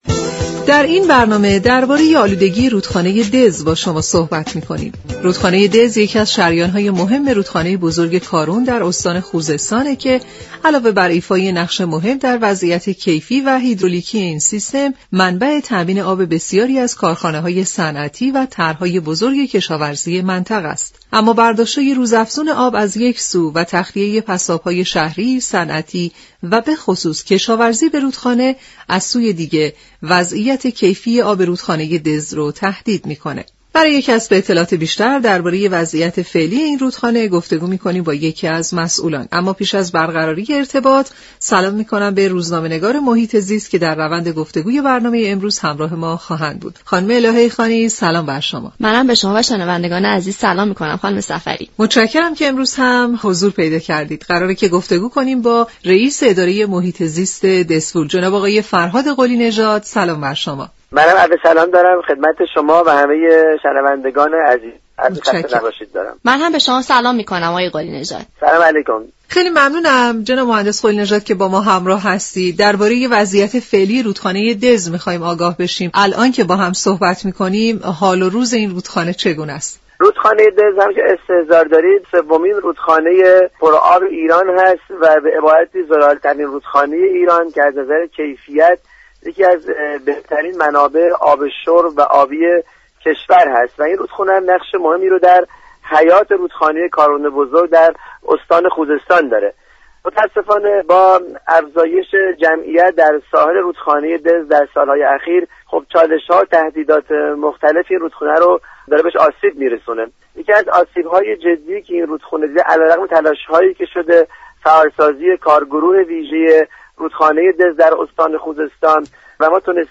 رییس اداره محیط زیست شهرستان دزفول؛ در گفت و گو با «سیاره آبی»گفت